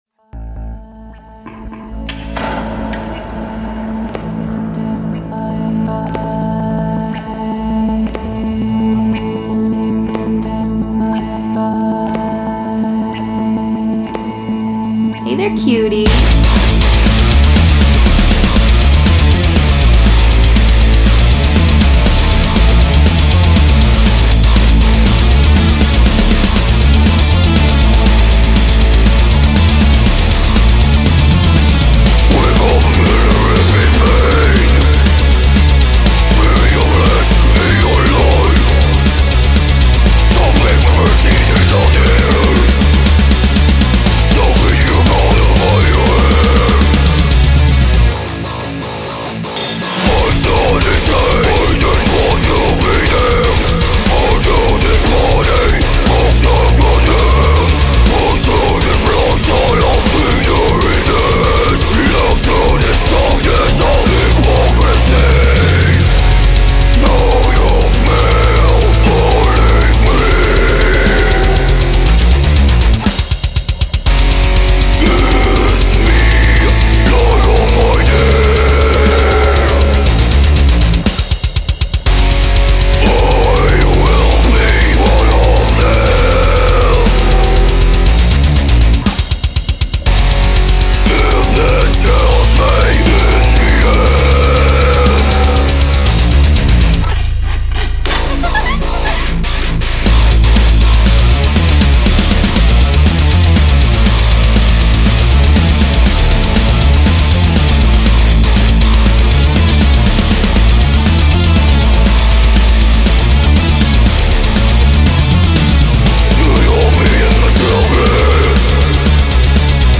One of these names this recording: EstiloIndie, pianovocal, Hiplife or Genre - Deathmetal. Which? Genre - Deathmetal